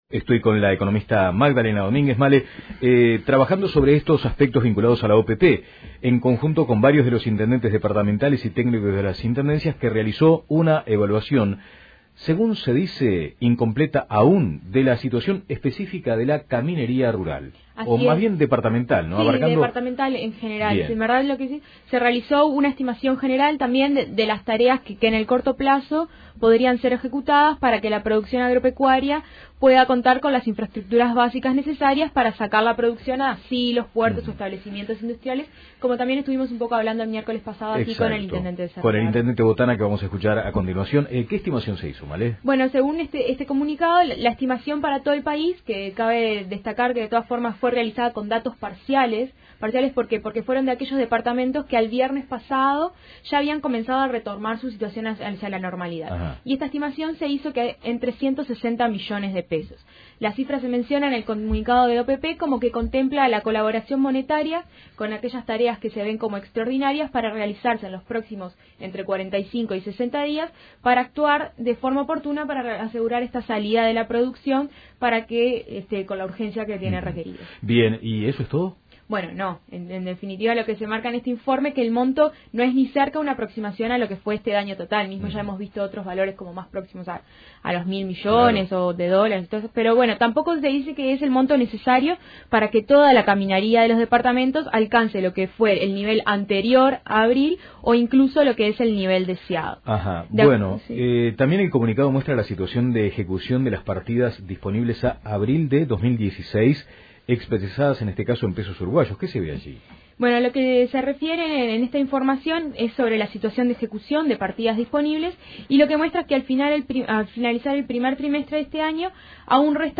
Informe económico